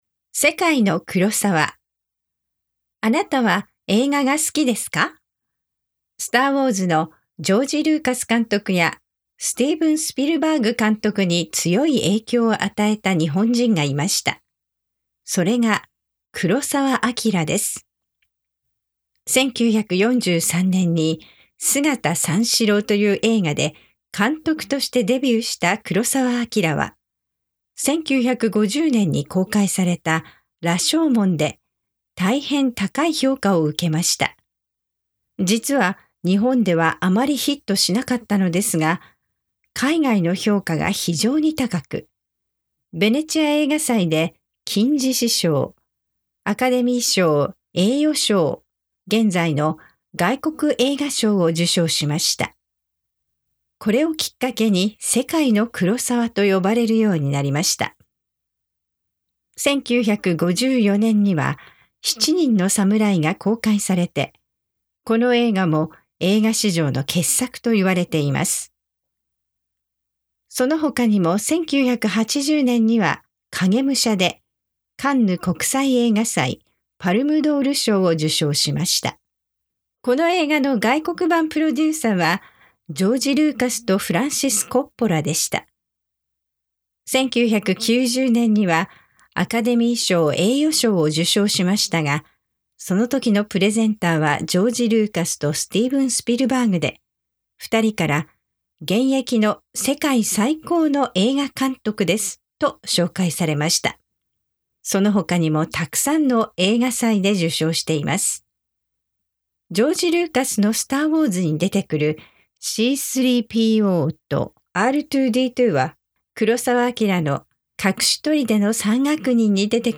Kurosawa – NORMAL SPEED – Click here to download – DOWNLOAD AUDIO
Kurosawa-Normal-Speed.mp3